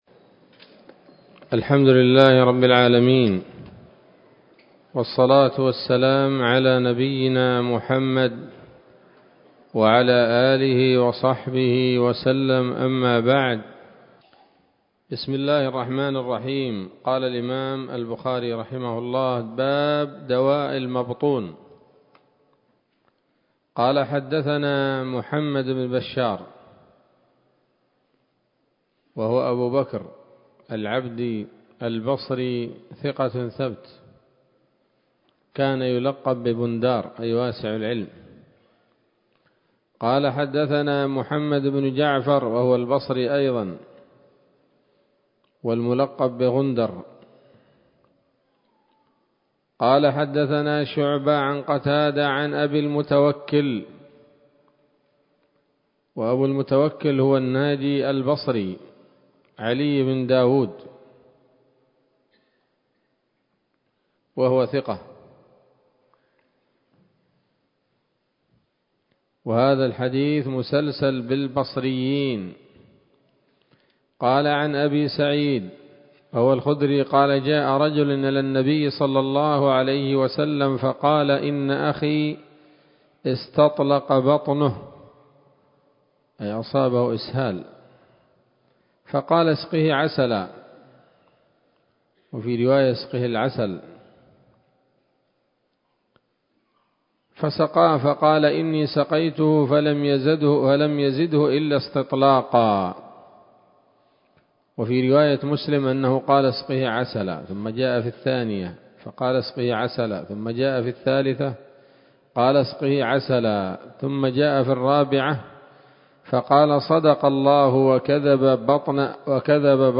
الدرس التاسع عشر من كتاب الطب من صحيح الإمام البخاري